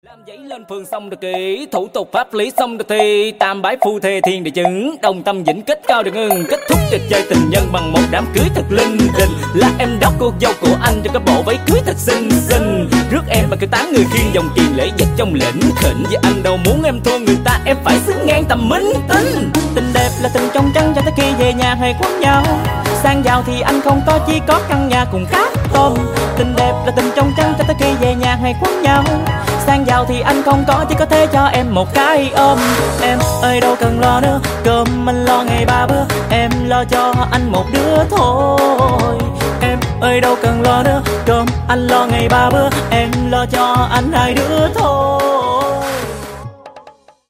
Rap Hot TikTok